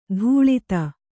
♪ dhūḷita